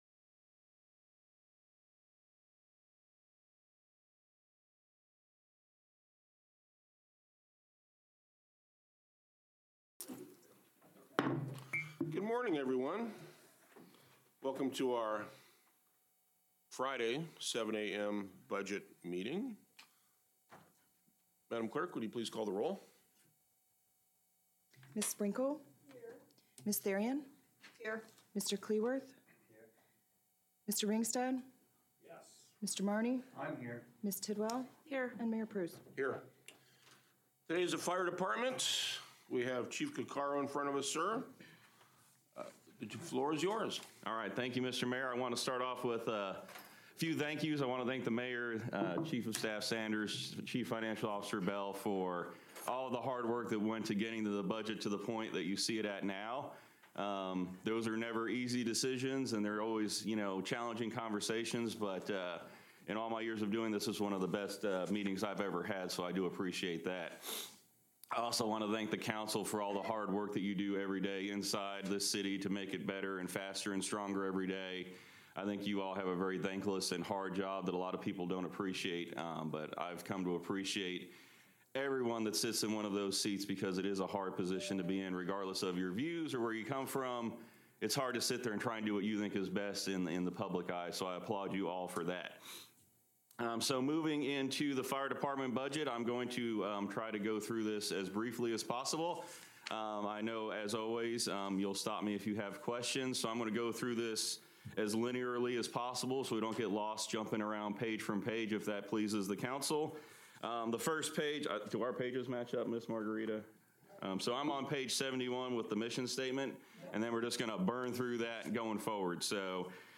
Council Budget Meeting